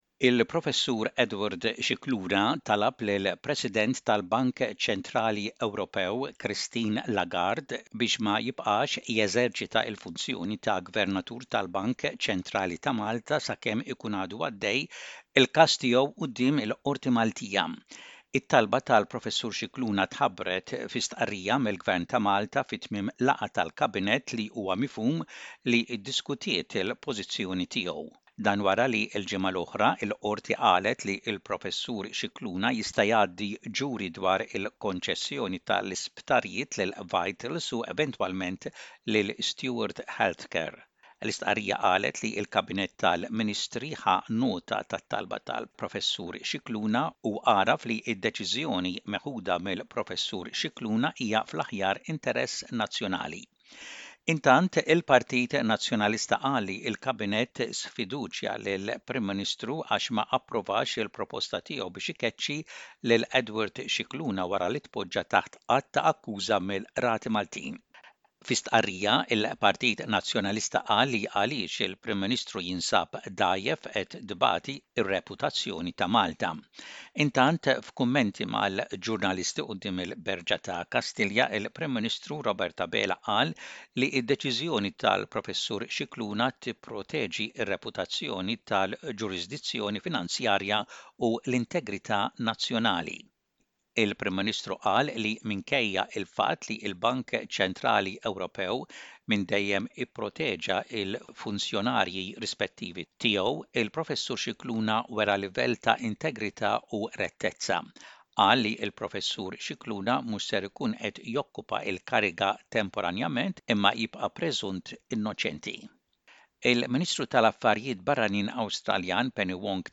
SBS Radio | Aħbarijiet bil-Malti: 03.08.24